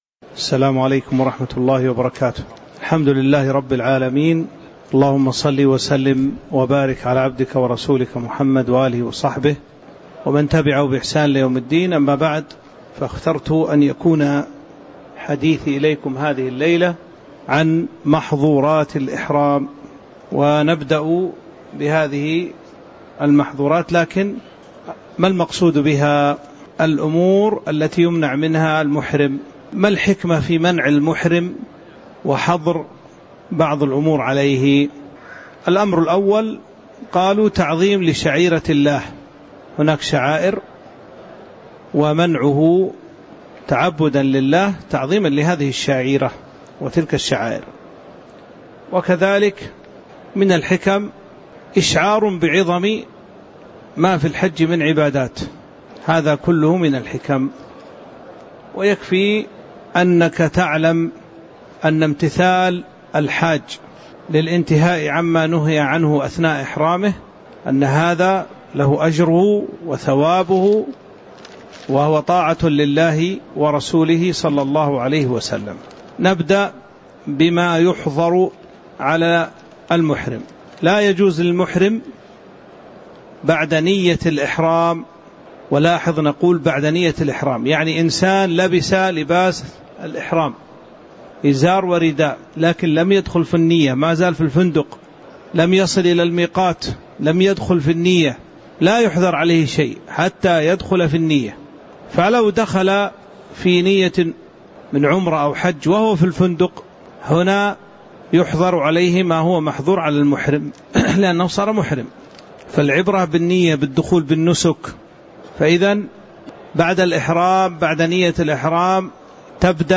تاريخ النشر ٥ ذو الحجة ١٤٤٦ هـ المكان: المسجد النبوي الشيخ